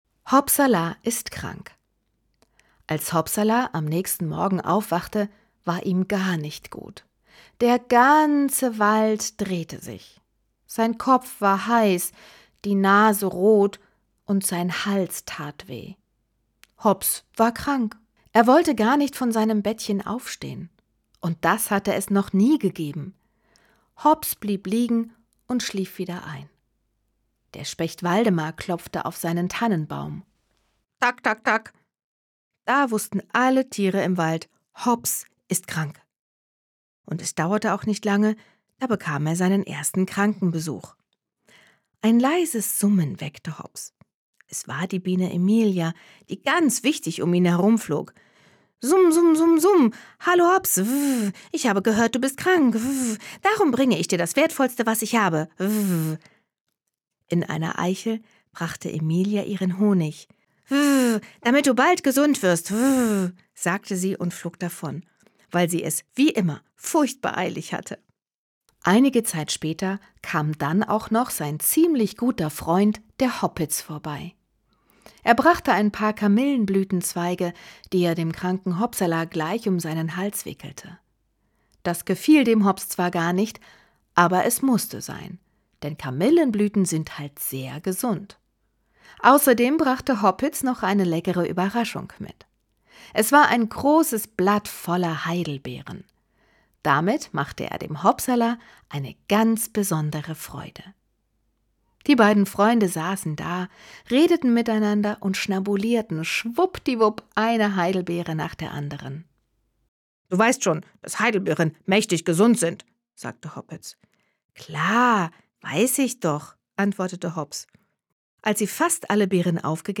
Andrea Ballschuh macht mit ihrer sympathischen Stimme diese schönen, spannenden und motivierenden Geschichten vom Knickohrhasen Hopsala zu einem besonderen Hörerlebnis.
Es hat mir sehr viel Spaß gemacht, diese Texte für euch zu lesen und im Tonstudio aufzunehmen.